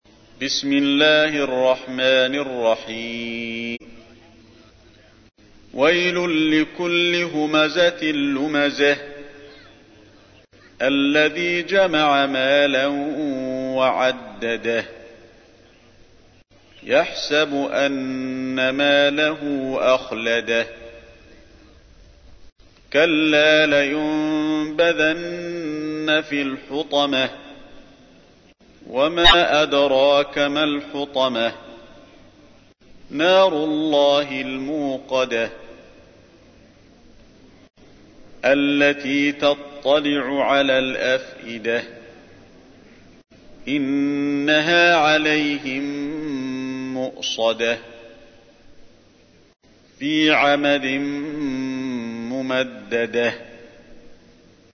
تحميل : 104. سورة الهمزة / القارئ علي الحذيفي / القرآن الكريم / موقع يا حسين